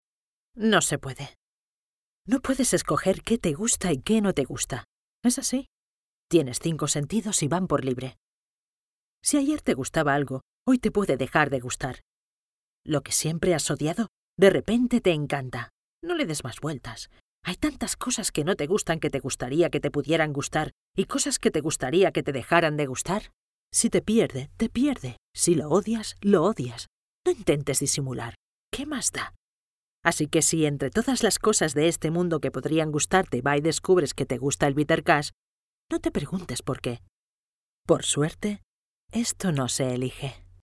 I have a versatile voice that can make different voices, and very different intentions in the same voice, if necessary.
Sprechprobe: Werbung (Muttersprache):
My recording equipment is: Neumann condenser microphone TLM 102, Previous Fucusrite Scarlet 2/2 and Software logic pro X and Audition.